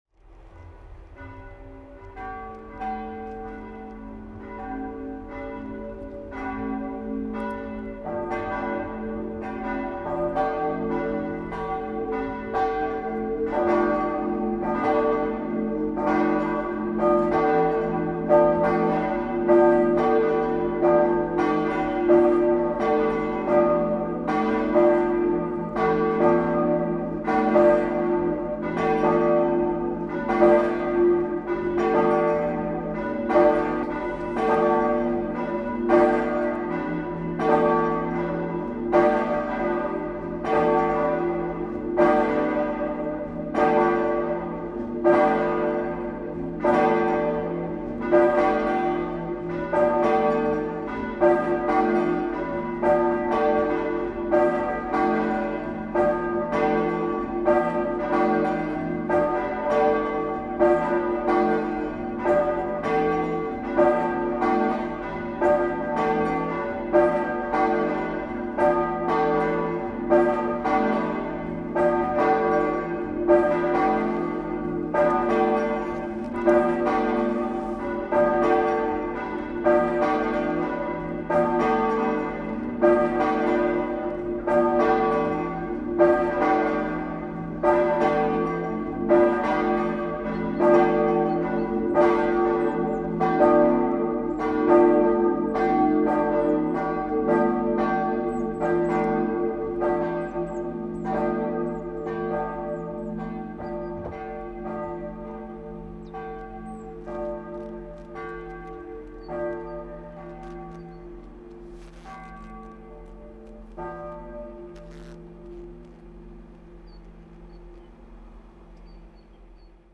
Grangärde kyrkklockor(1).mp3